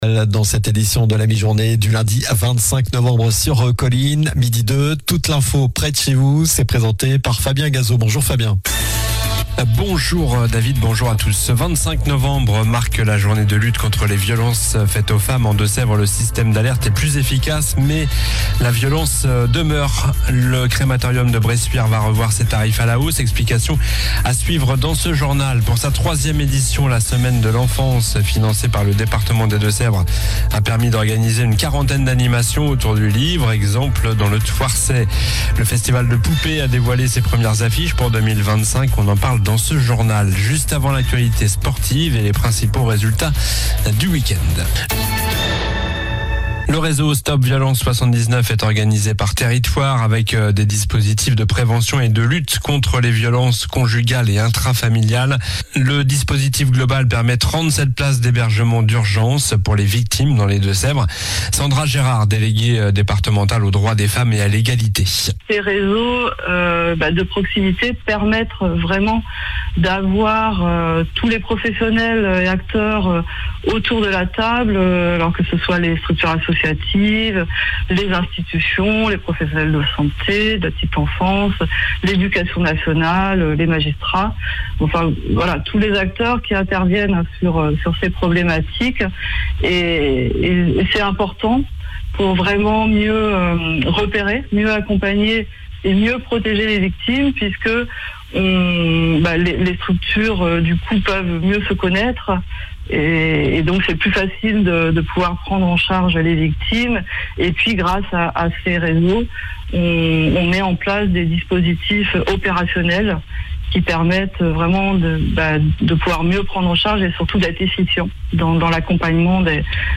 Journal du lundi 25 novembre (midi)